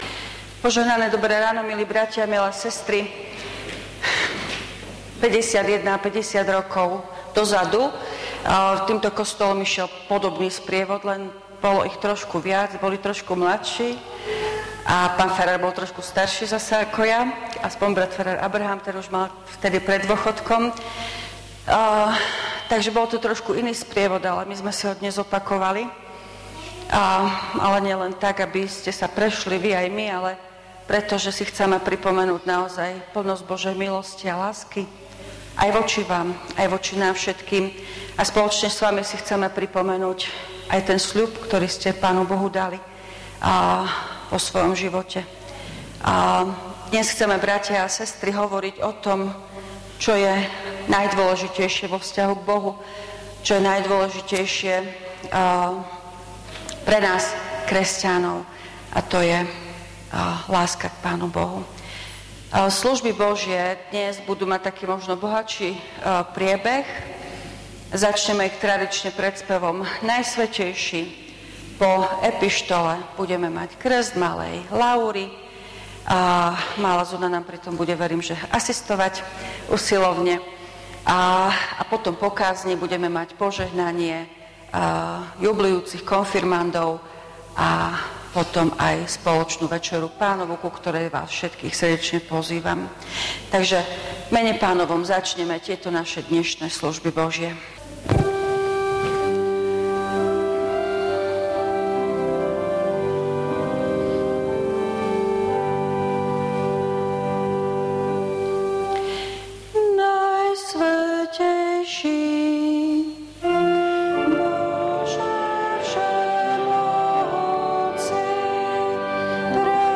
V nasledovnom článku si môžete vypočuť zvukový záznam zo služieb Božích – 13. nedeľa po Sv. Trojici_ Zlatá konfirmácia.
PIESNE: 237, 294, 624, 642, 298, 263.